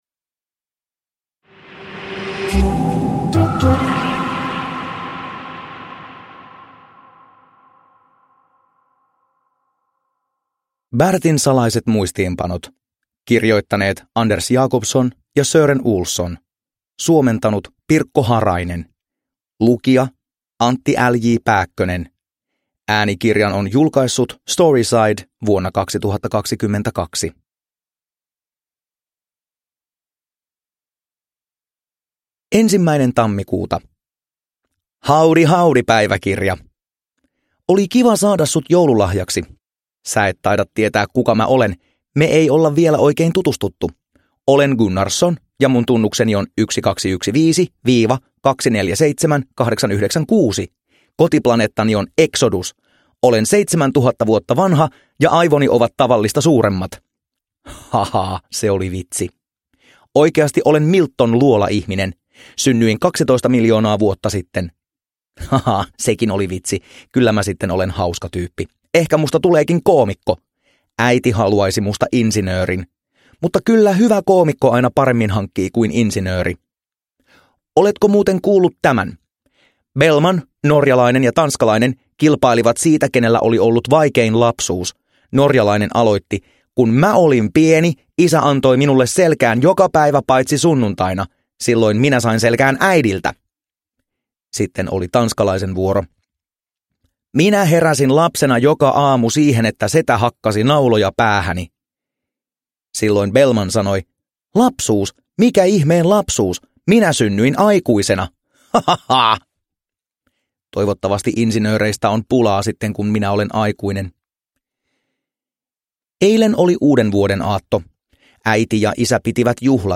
Bertin salaiset muistiinpanot – Ljudbok – Laddas ner